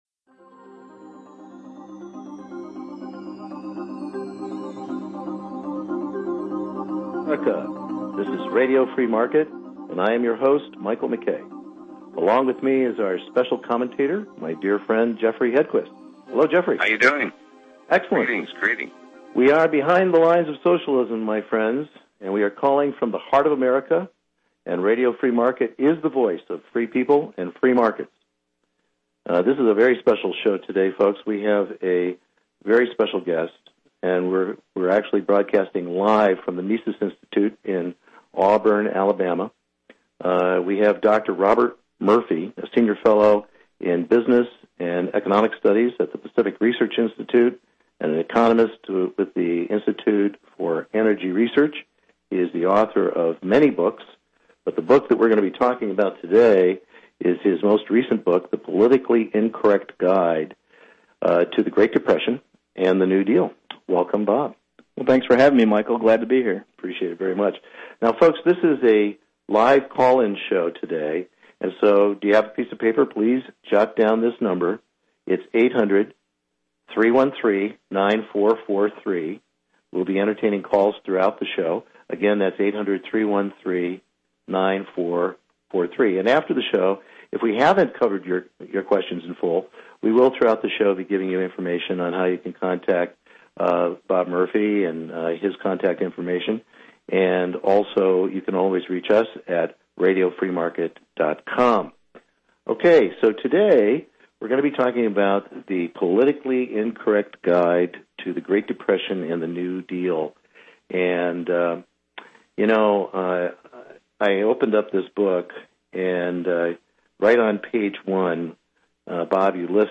This very entertaining interview discusses how ‘Just about everything you were taught about the Great Depression was False’ and ‘How the Federal Reserve was the Biggest Culprit in the GD’.